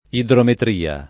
[ idrometr & a ]